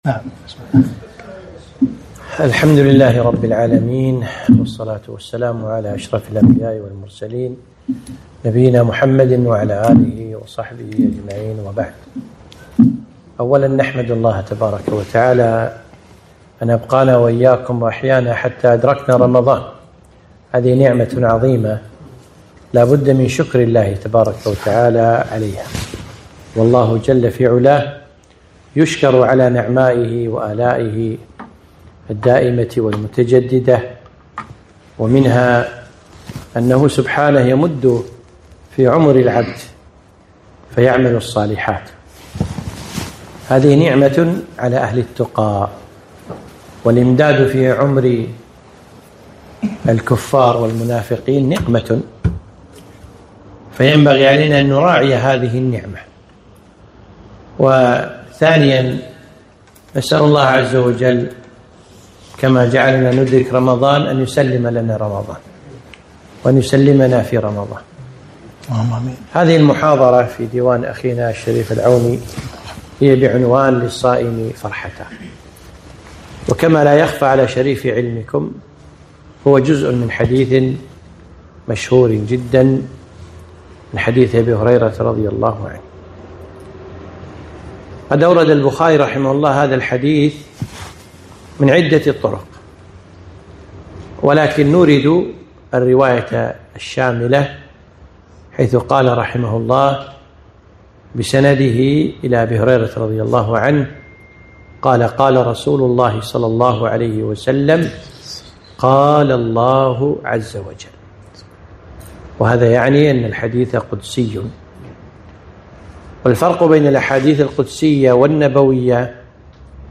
محاضرة - ( للصائم فرحتان )